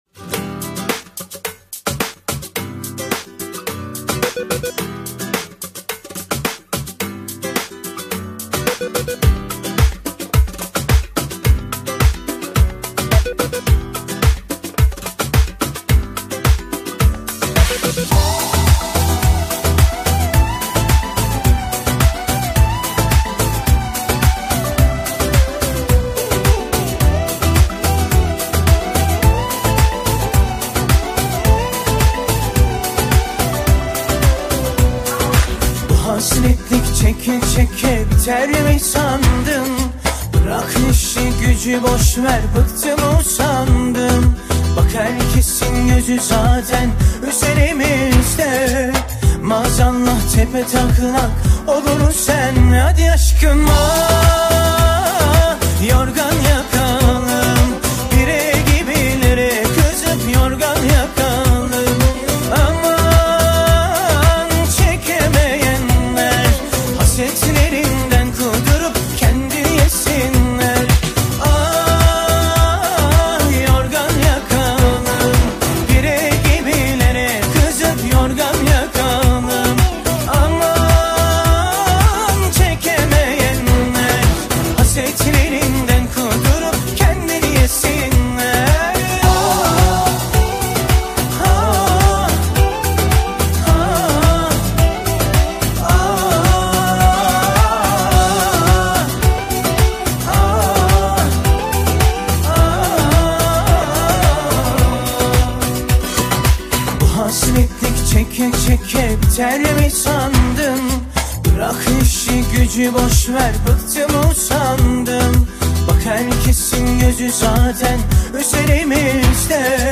شاد ترکی